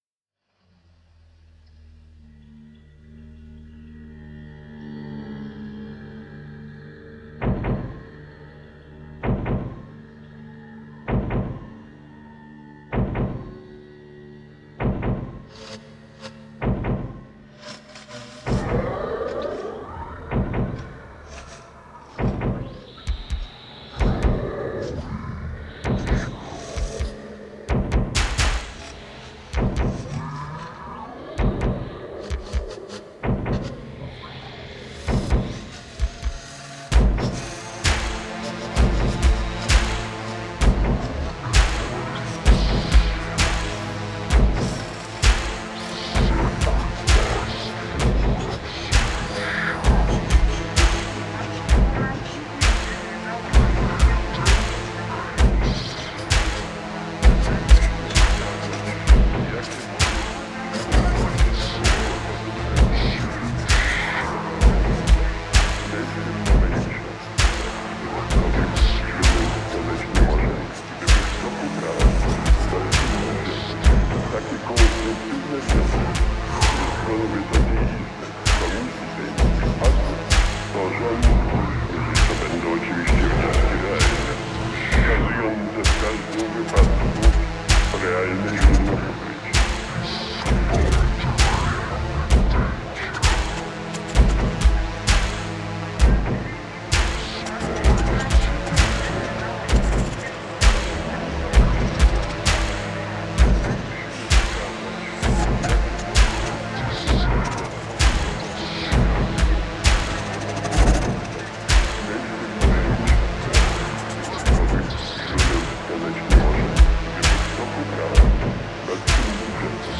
Genre: Ambient, New Age.